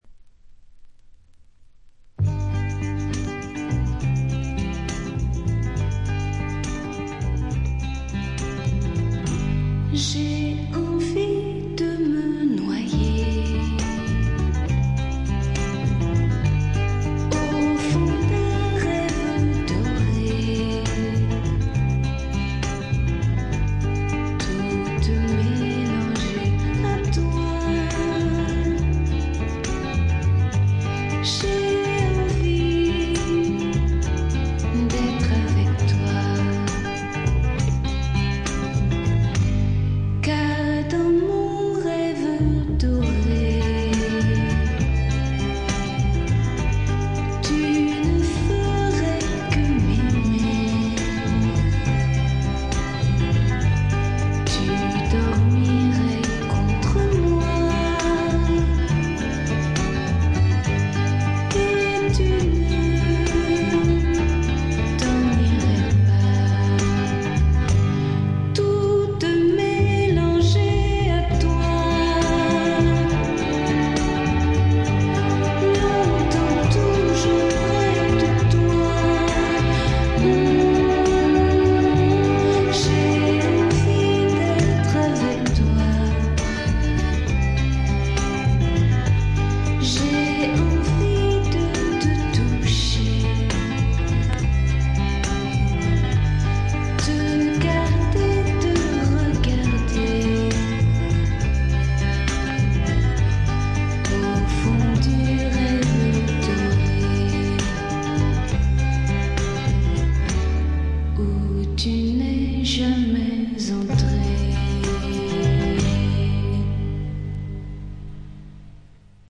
わずかなチリプチとプツ音のみ（特にA2）。
試聴曲は現品からの取り込み音源です。